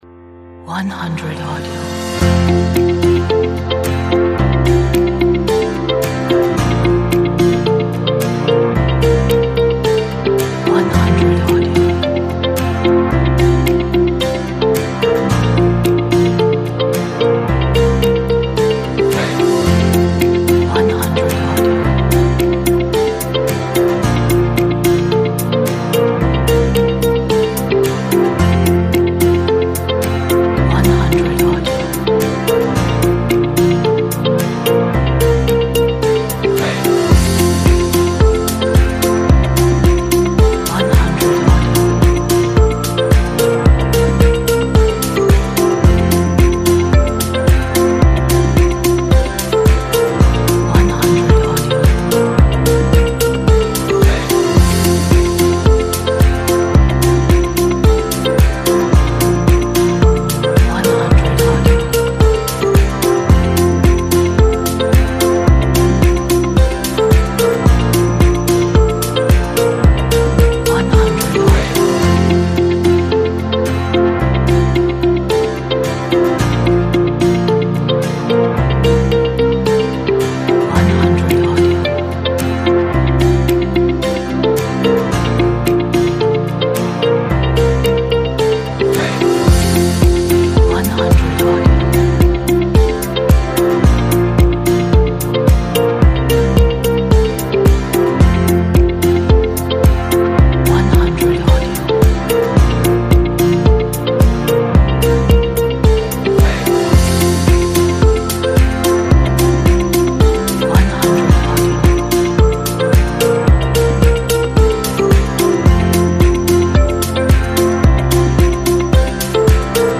Motivated motivational upbeat power energy.